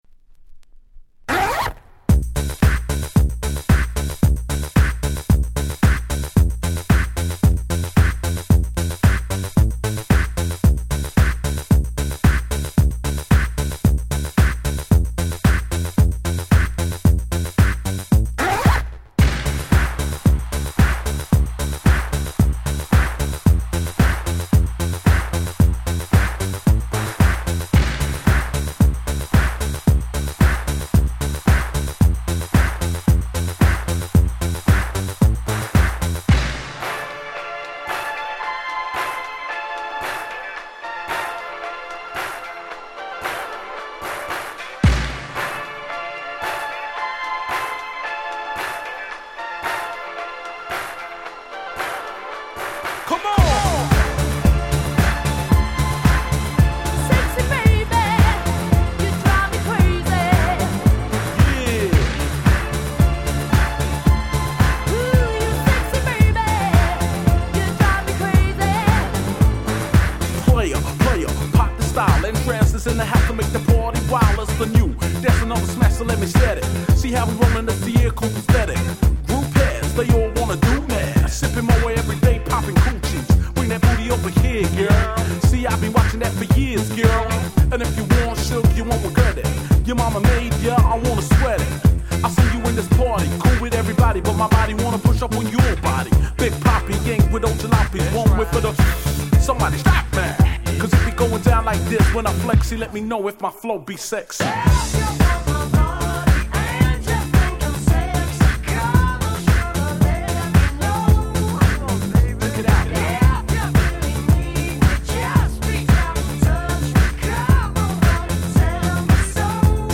97' 鉄板キャッチーR&Bクラシック！！